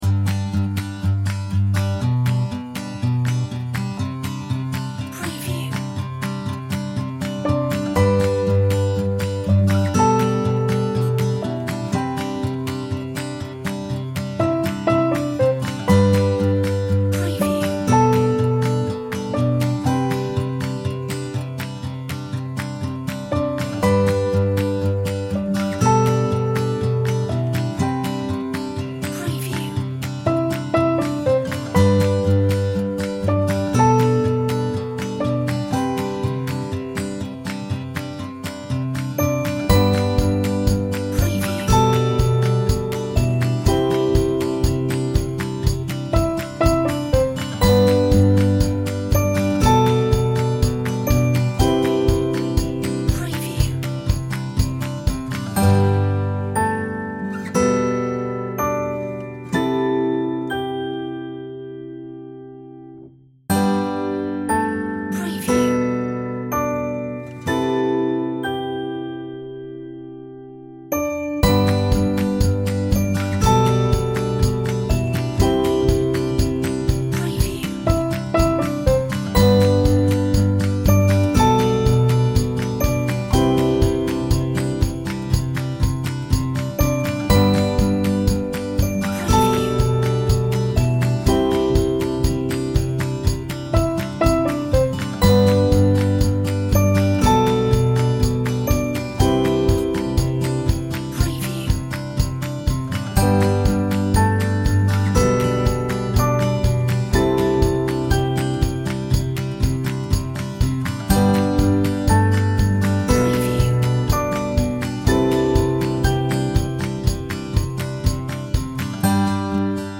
Simple acoustic melodies